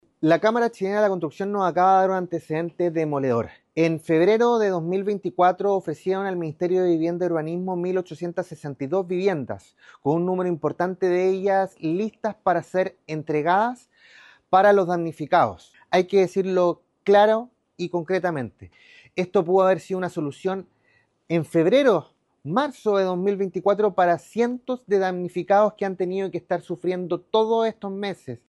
El diputado radical, Tomás Lagomarsino, se refirió a los nuevos antecedentes que fueron presentados durante la sesión, señalando que -de acuerdo a la CChC- el ofrecimiento fue efectuado en febrero de 2024.